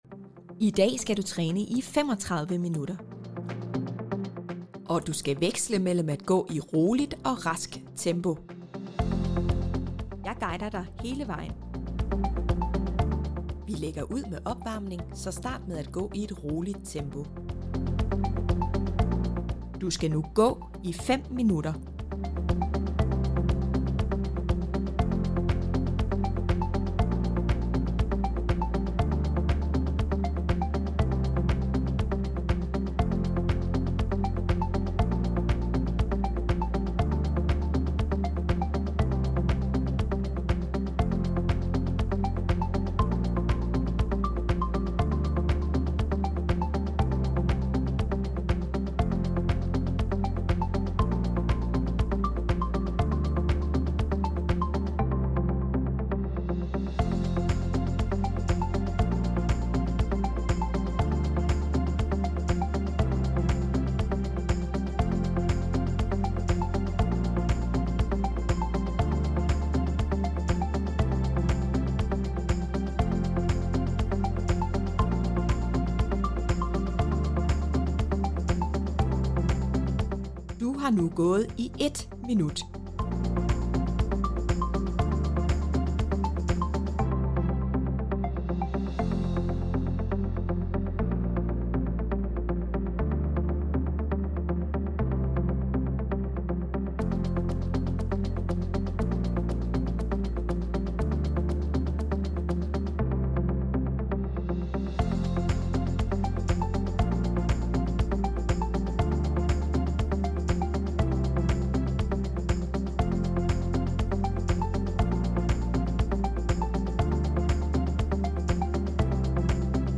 Forstå gevinsterne ved den japanske træningstrend – og prøv det selv med I FORMs lydprogram, som guider dig igennem.